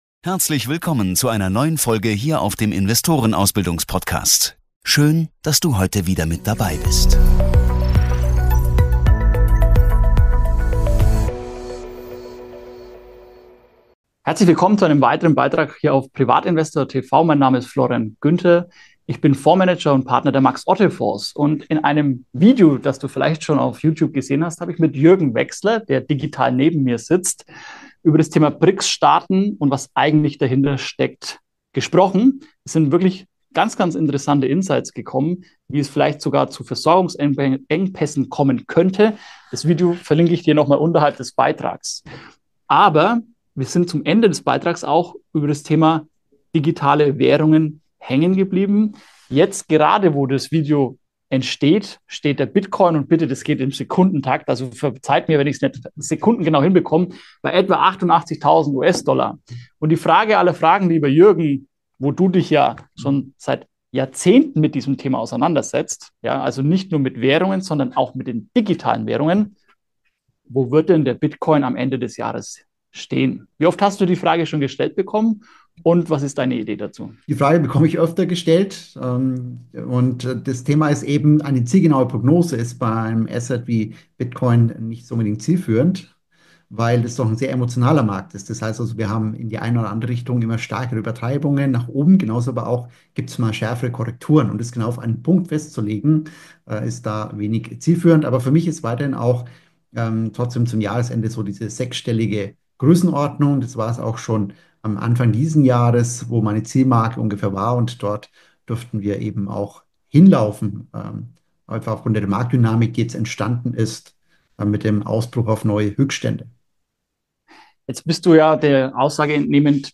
Erfahren Sie die überraschenden Antworten in diesem aufschlussreichen Dialog zweier Experten.